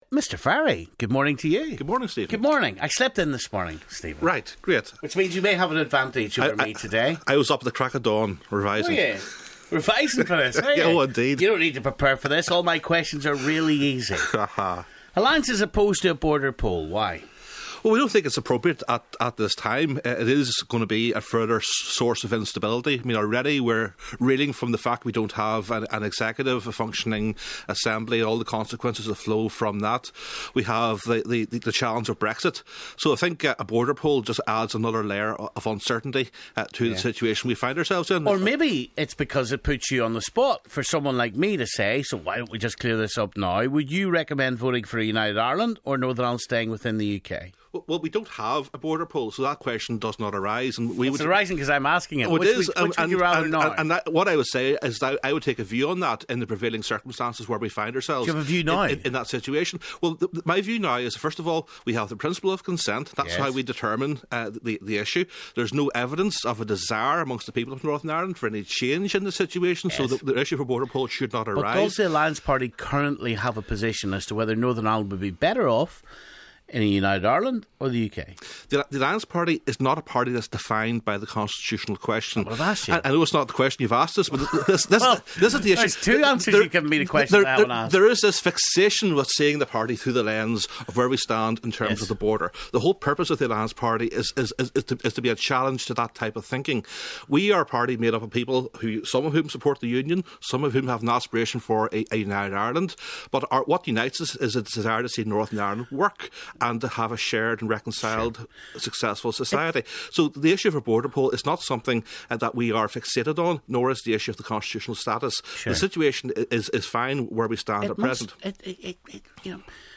Next in our General Election manifesto interviews - it's the Alliance Party deputy leader Stephen Farry in the studio this morning. He's the latest politician in the hot seat for our big election interview series.